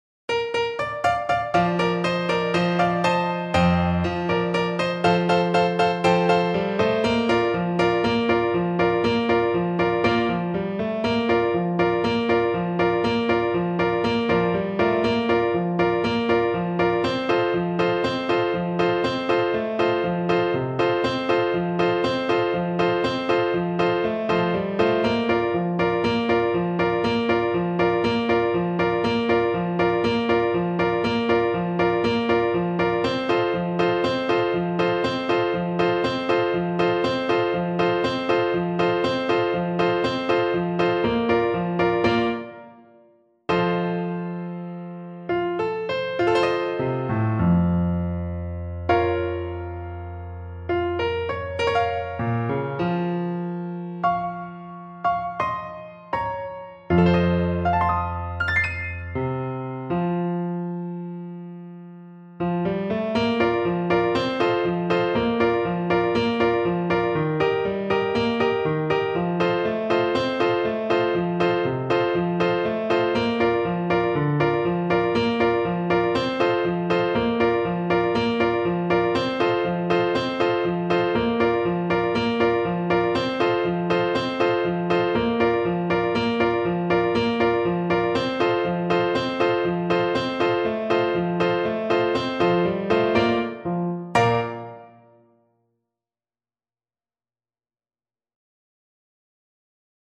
Clarinet version
Mariachi style =c.120
2/4 (View more 2/4 Music)
Mexican